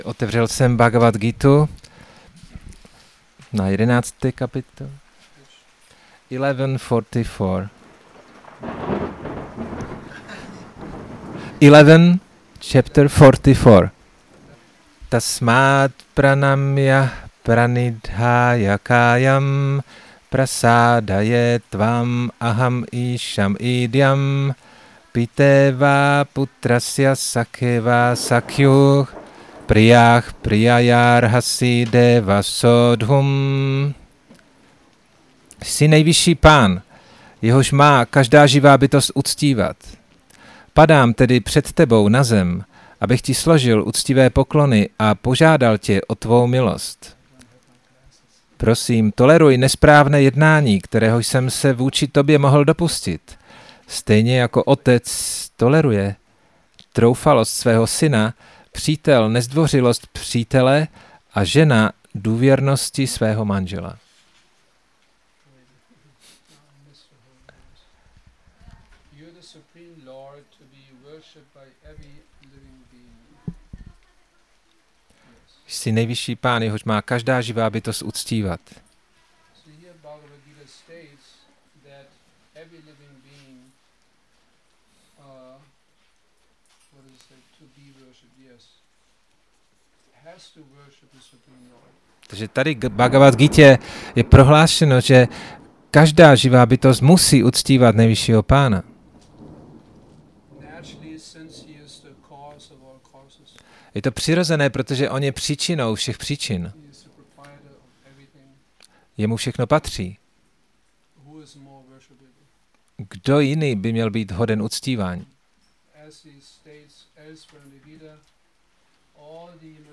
Šrí Šrí Nitái Navadvípačandra mandir